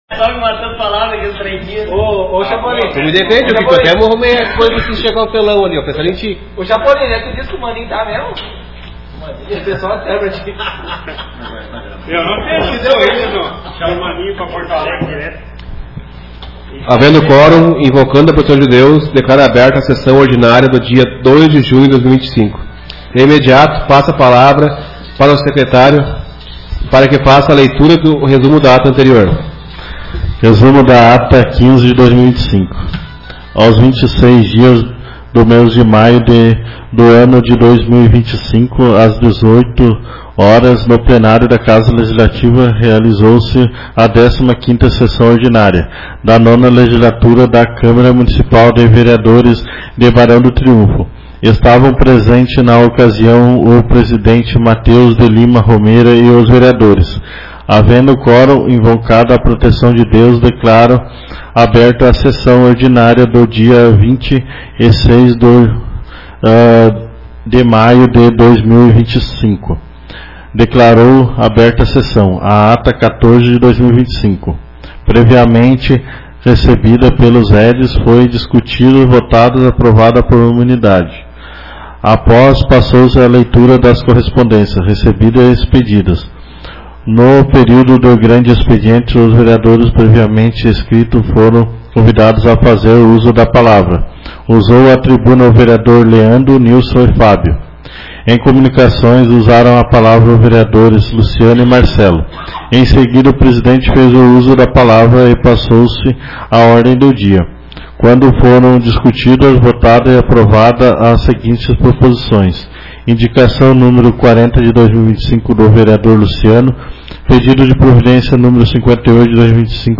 Áudio das Sessões